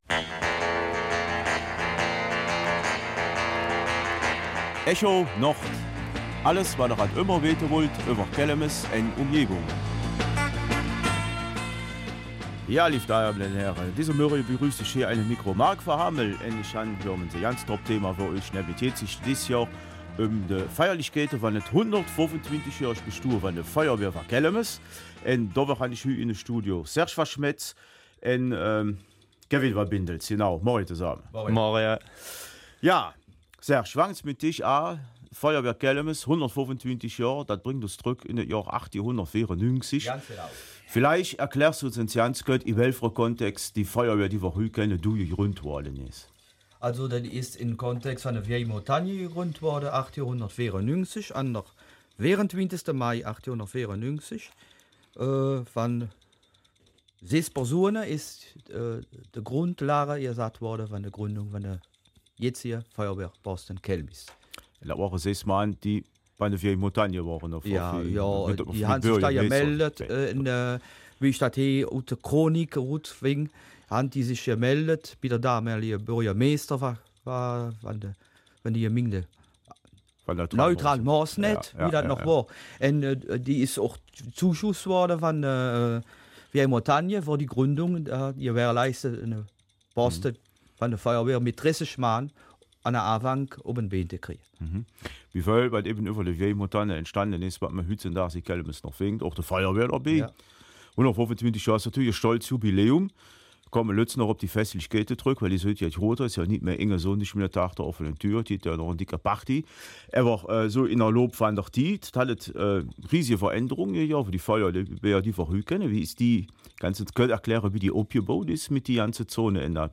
Kelmiser Mundart